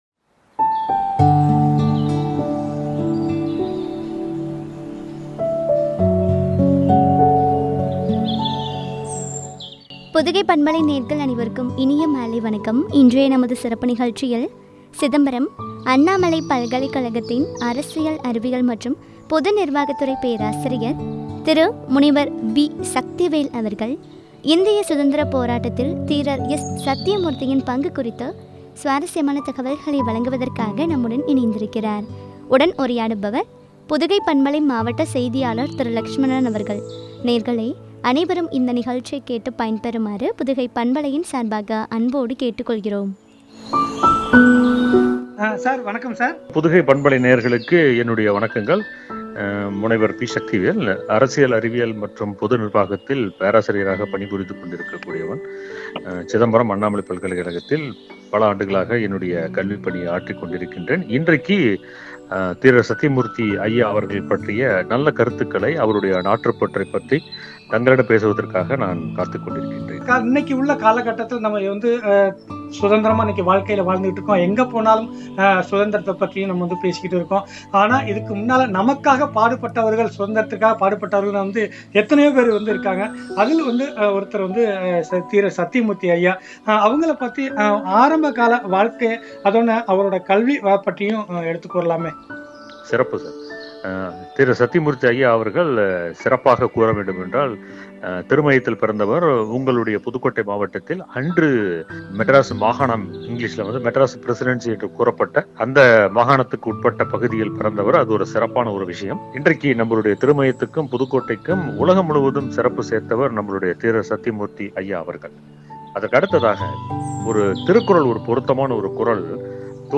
சத்தியமூர்த்தி” யின் பங்கு பற்றிய உரையாடல்.